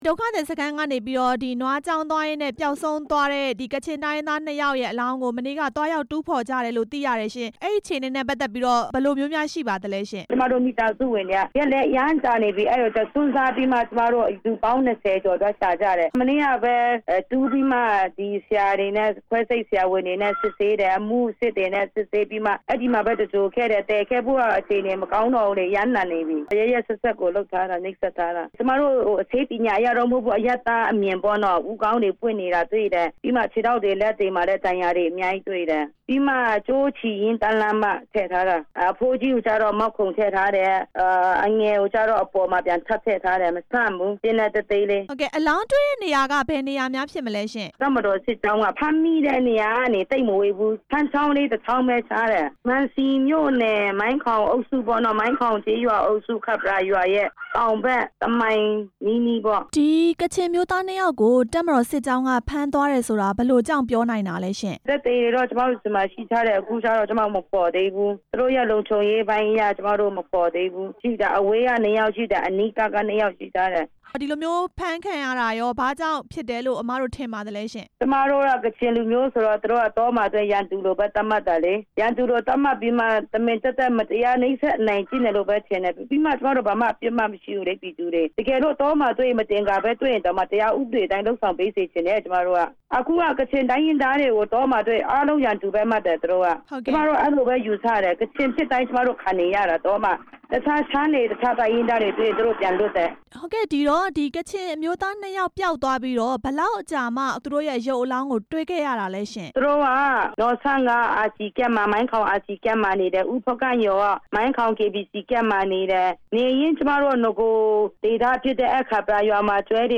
ကချင် နှစ်ယောက် အသတ်ခံရမှု ဆွေမျိုးတော်စပ်သူနဲ့ မေးမြန်းချက်